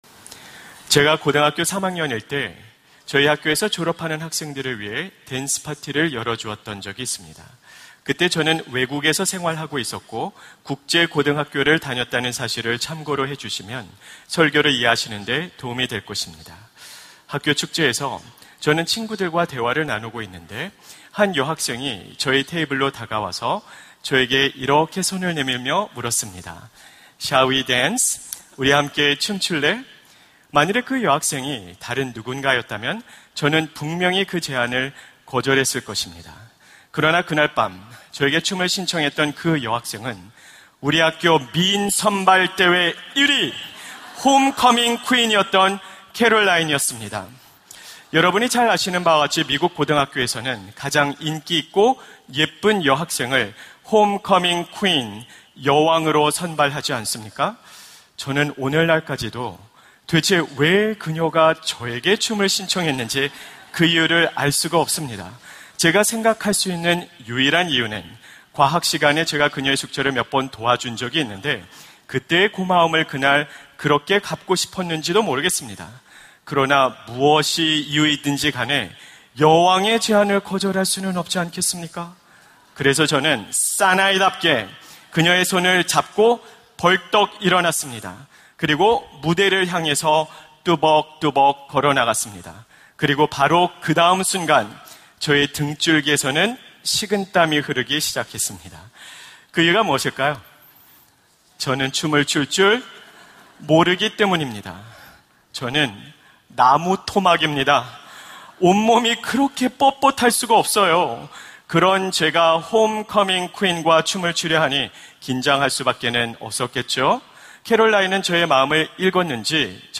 설교 : 주일예배 우리 함께 춤출까요?